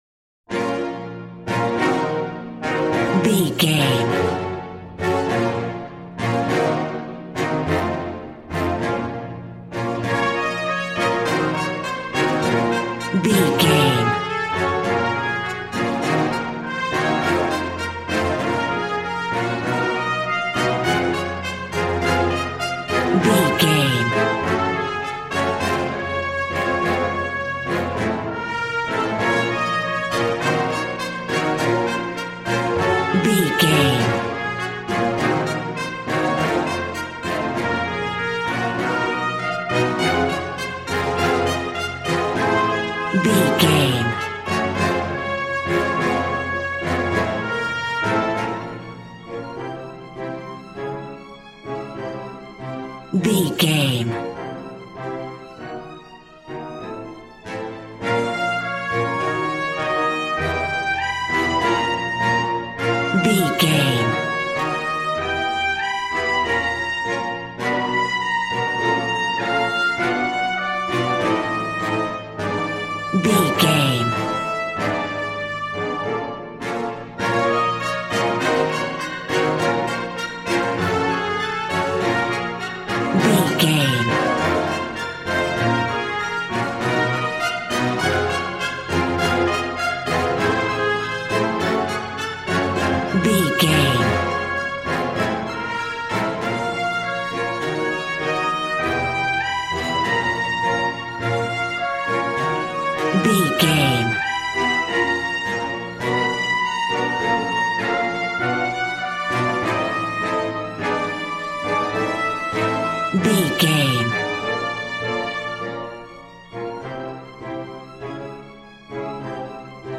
Ionian/Major
dramatic
powerful
epic
percussion
violin
cello